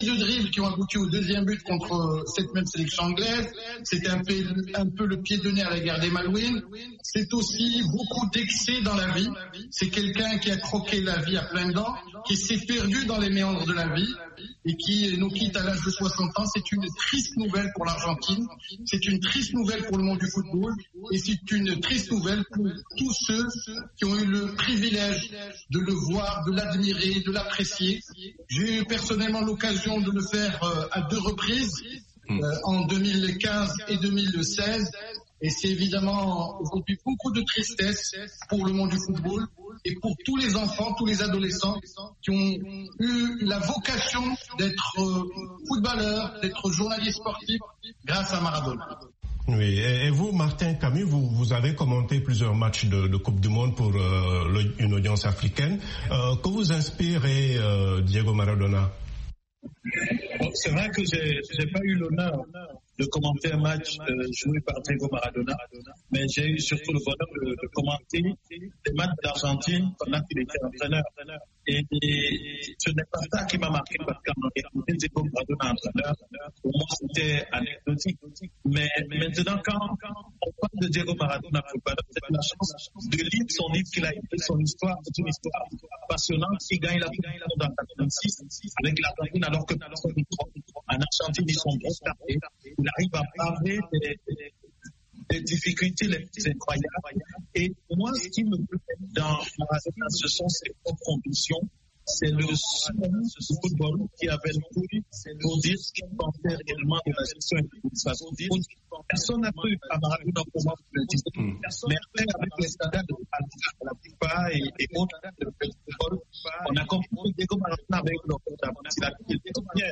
la musique de chanteurs